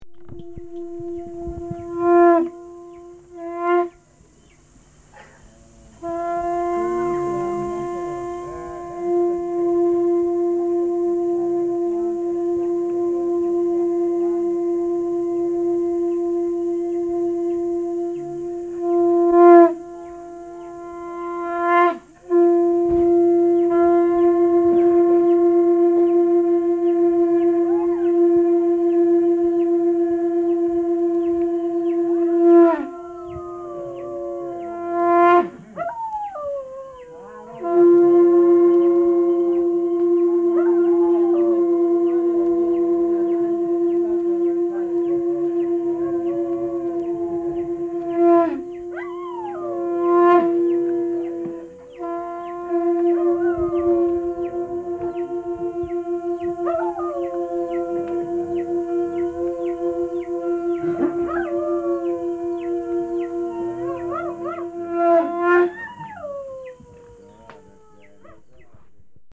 U'wa drones from the Andes in north-east Colombia.